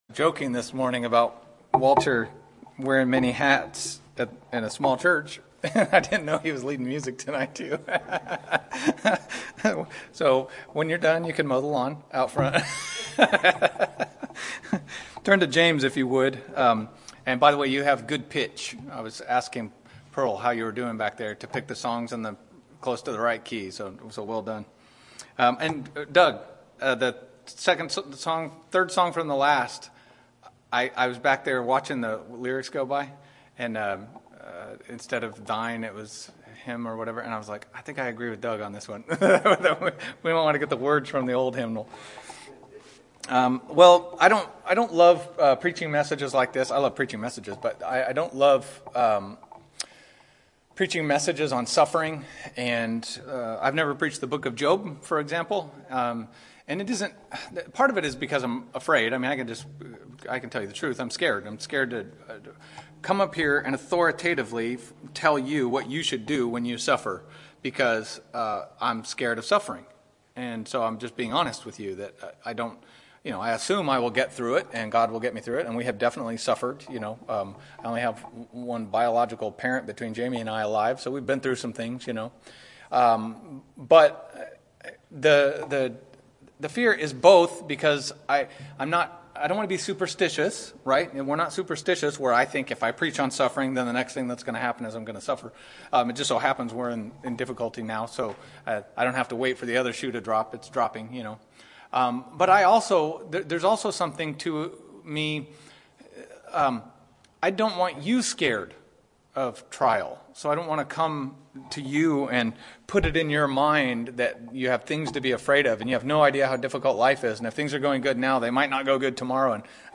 " Misc 2024 " Sermon Notes Facebook Tweet Link Share Link Send Email